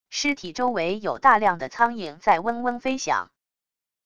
尸体周围有大量的苍蝇在嗡嗡飞响wav音频